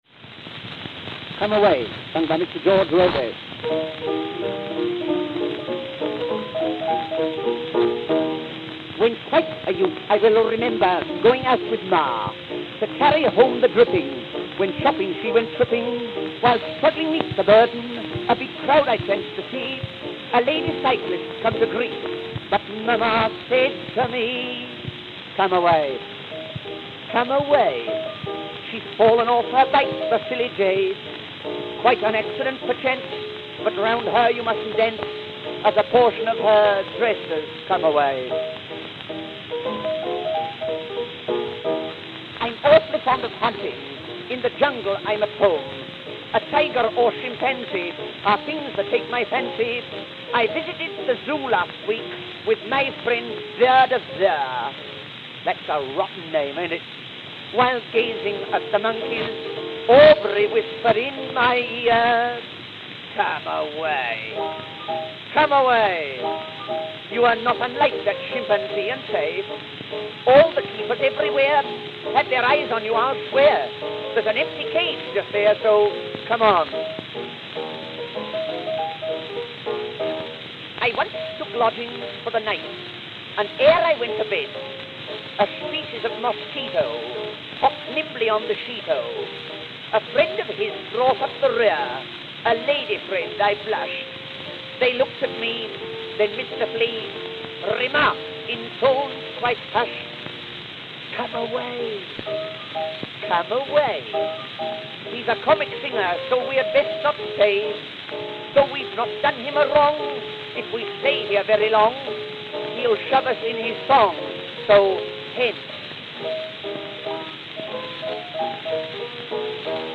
Royal Saxonian 7th Infantry Regiment Band of Prince George, No. 106 (of Germany)
Kramer Strasse, 4, Leipzig, Saxony, German Empire
Also note: A plunk sound, not a skip, even before filtering at 0:47 regardless of what stylus is used.